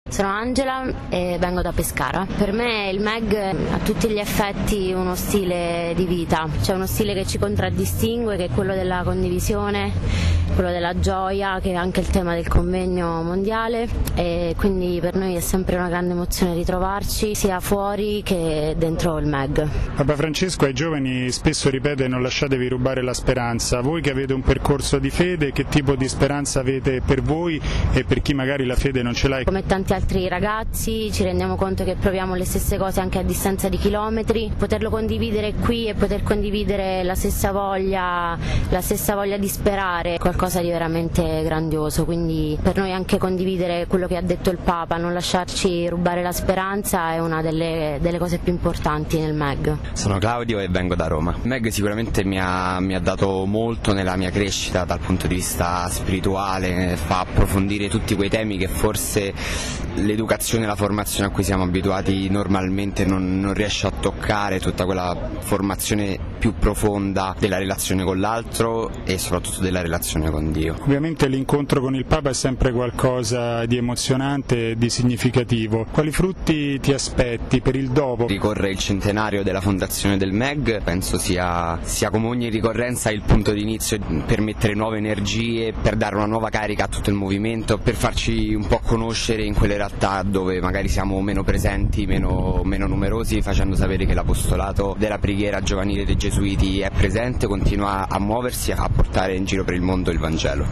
ha raccolto le testimonianze di due giovani impegnati nel Meg, che hanno preso parte all’udienza del Papa in Aula Paolo VI